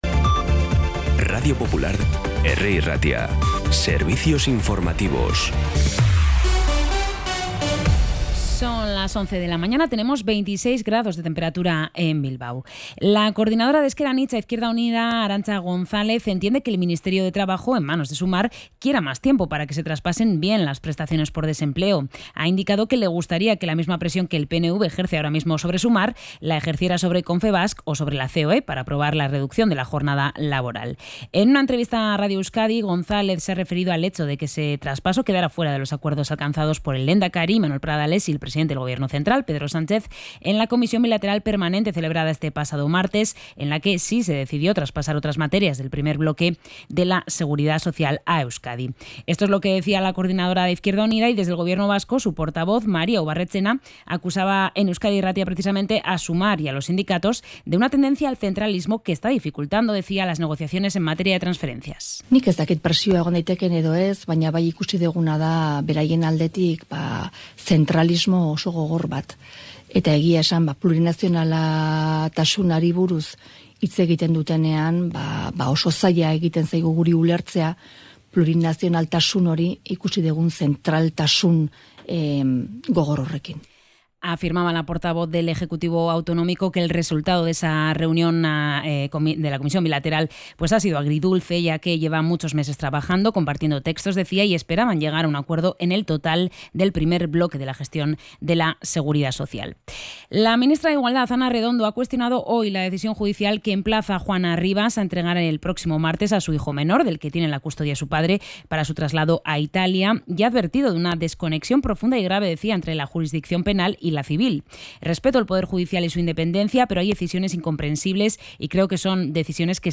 Información y actualidad desde las 11 h de la mañana